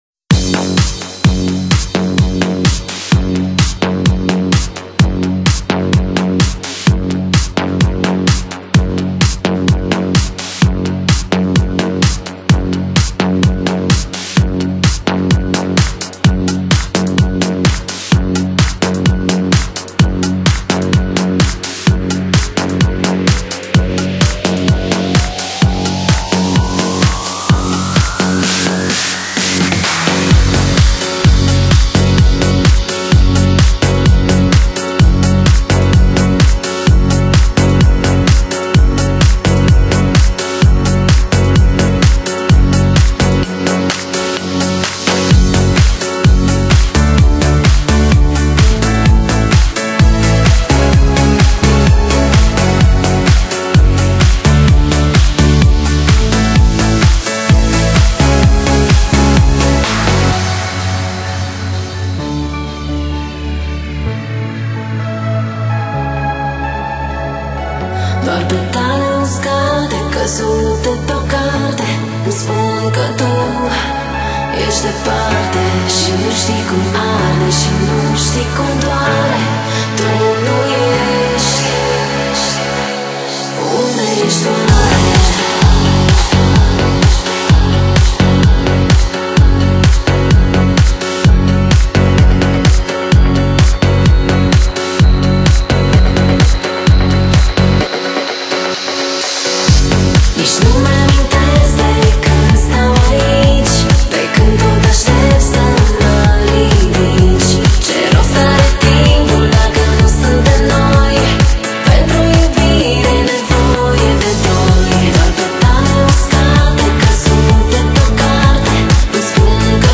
节奏强烈适合手舞足蹈啊，这样的音乐才给力啊